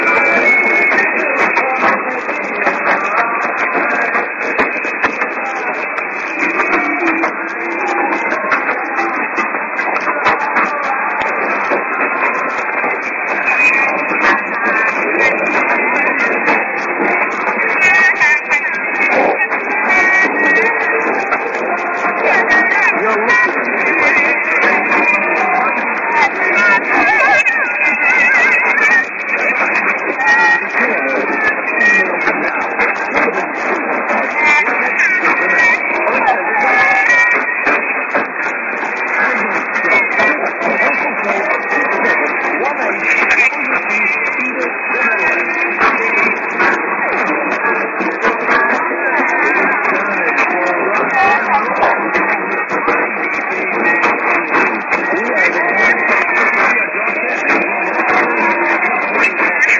->ID:Your listening to Mighty KBC･･･(man)->　Heavy QRM+Side
当家の受信した音声をＵＰしますが、サイドが凄いので聞きにくいですが、心してお聞き下さい。
２０秒過ぎに、私の耳には"Your listening to Mighty KBC・・・”と聞き取れました。